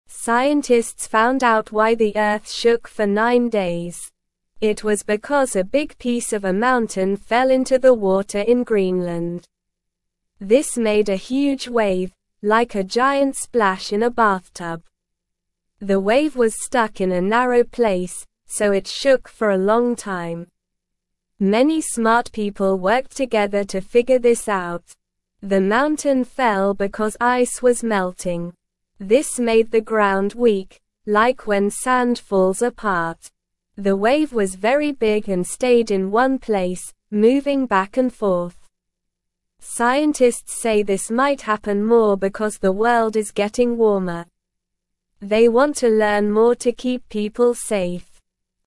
Slow
English-Newsroom-Beginner-SLOW-Reading-Big-Wave-Shook-Earth-for-Nine-Days-Straight.mp3